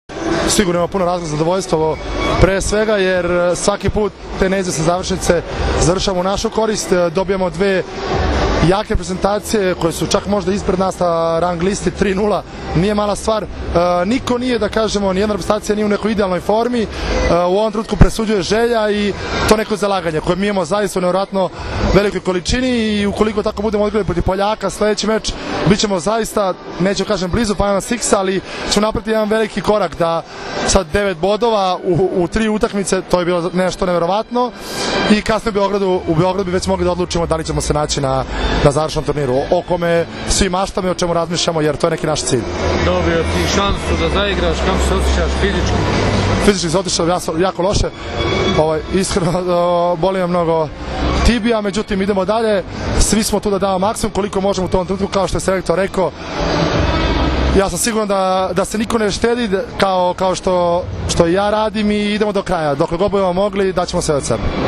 IZJAVA ALEKSANDRA ATANASIJEVIĆA